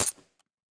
sounds / weapons / _bolt / dmr_1.ogg